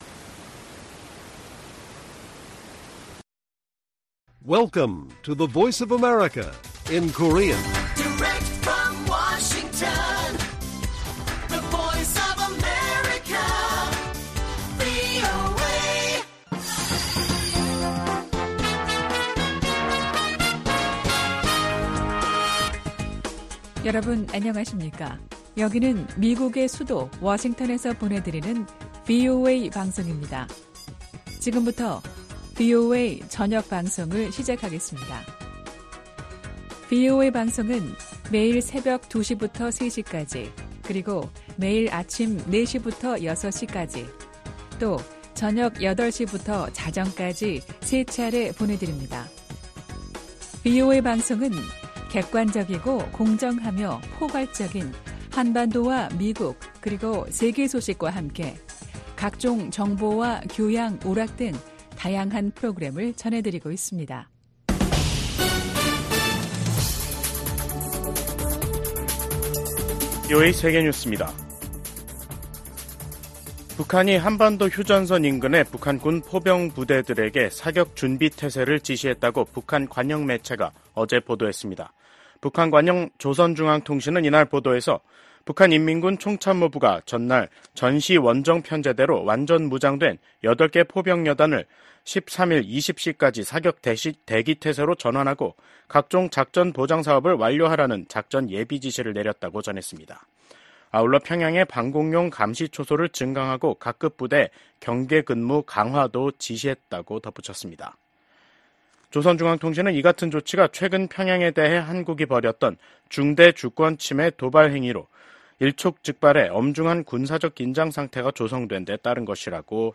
VOA 한국어 간판 뉴스 프로그램 '뉴스 투데이', 2024년 10월 14일 1부 방송입니다. 북한이 한국 측 무인기의 평양 침투를 주장하면서 한국과의 접경 부근 포병 부대들에게 사격 준비 태세를 지시했습니다. 미국 북한인권특사는 북한에서 공개재판과 공개처형이 늘어나는 등 북한 인권 실태가 더욱 열악해지고 있다고 지적했습니다.